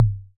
Tom Groovin 2.wav